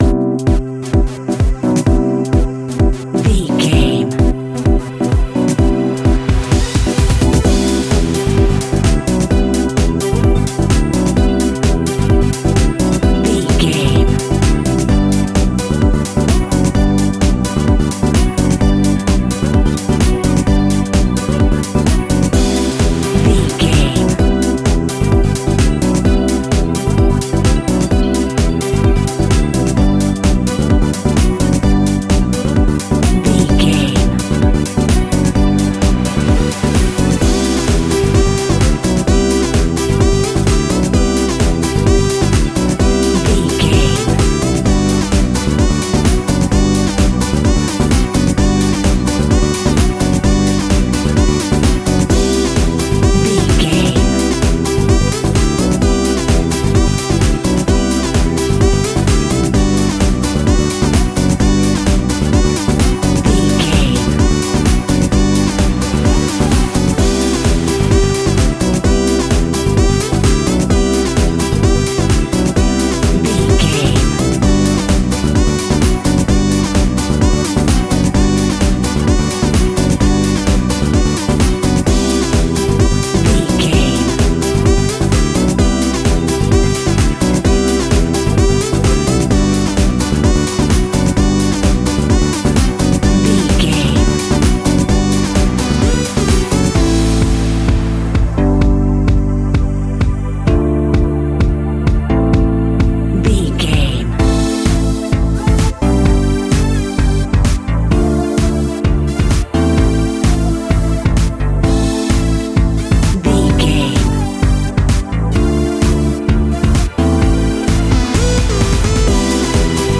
Holiday Funky House Music.
Ionian/Major
groovy
uplifting
energetic
festive
drums
bass guitar
strings
synthesiser
disco
upbeat